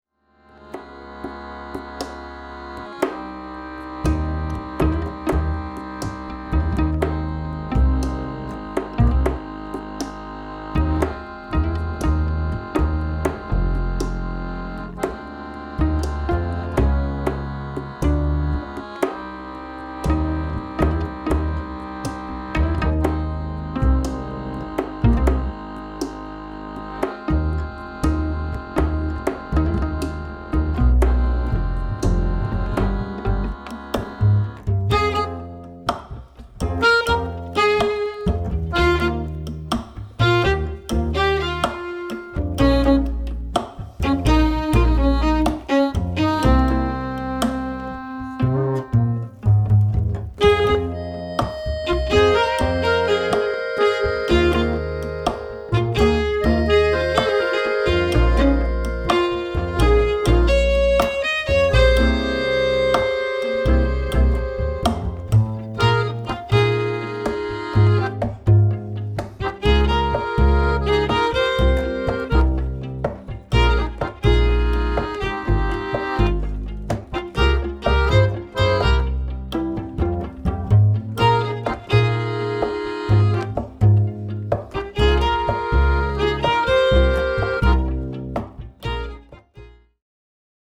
Geige, Bratsche, Akkordeon und Kontrabass